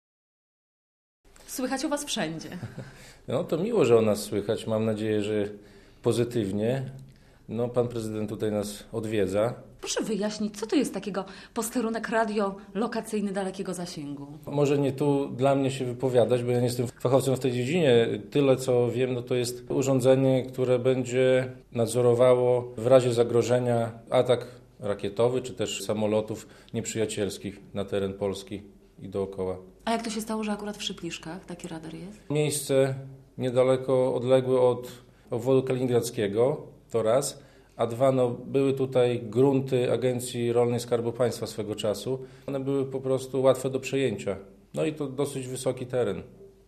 Z wójtem Szypliszek Mariuszem Grygieńciem - rozmawia